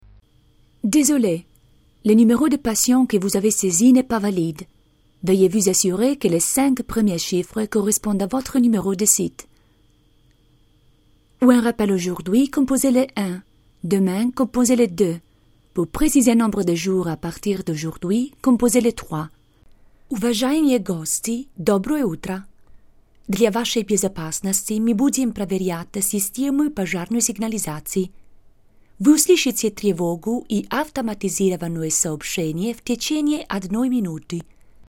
Voce fresca, elegante, chiara, sofisticata, sexy, civettuola
Sprechprobe: Sonstiges (Muttersprache):